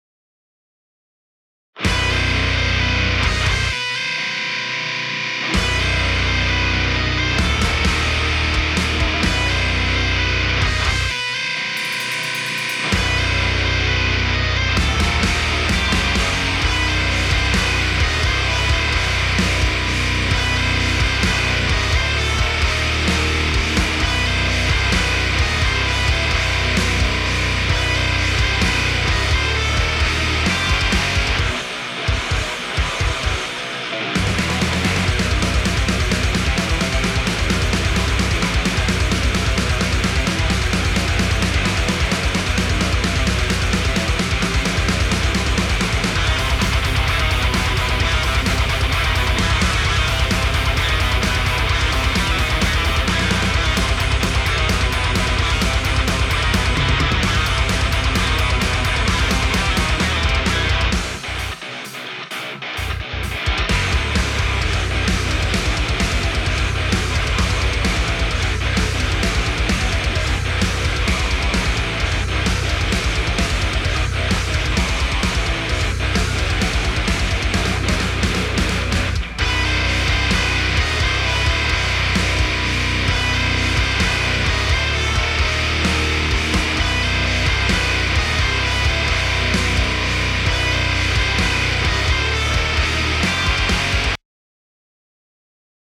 Metal Mix (momentan instrumental) fühlt sich stellenweise nicht koherent/richtig an